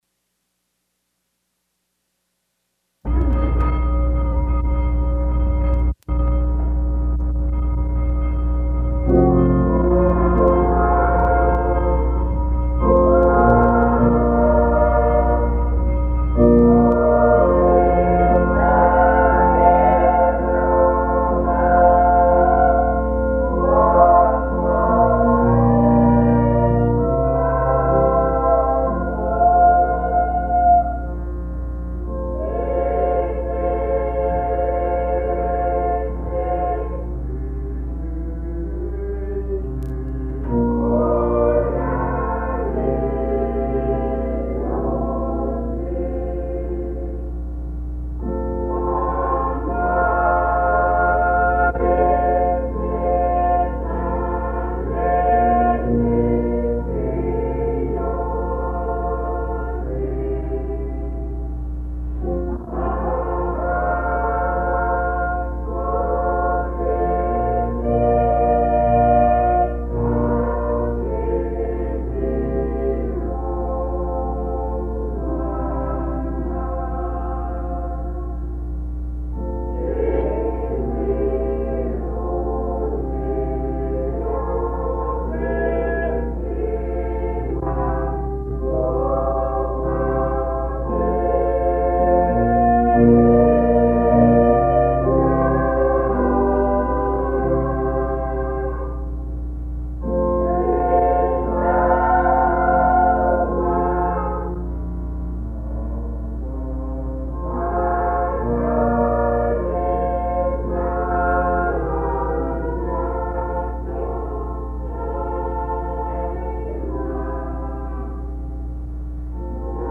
S(solo)/SATB//Organ
Common time-3/4-2/2; FM; 79 mm
Performer: Nain Moravian Choir
Location: Moravian Church, Nain, Labrador